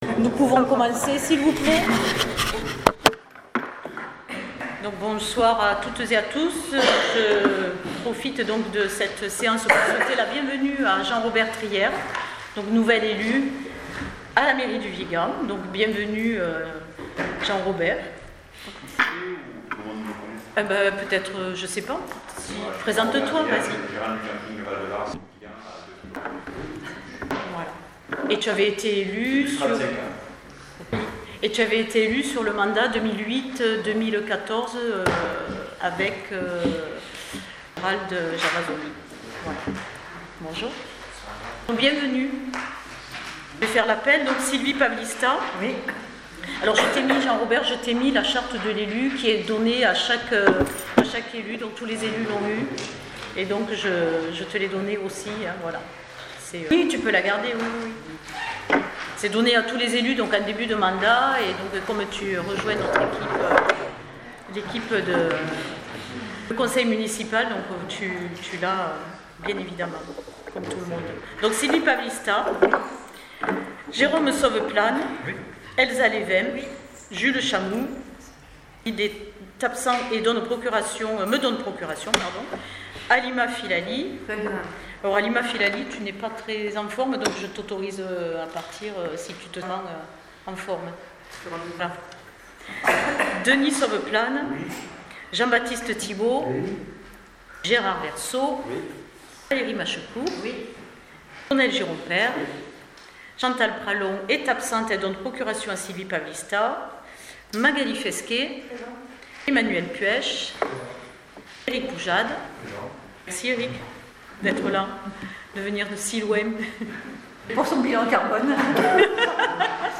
Procès verbal du conseil municipal du 29 février 2024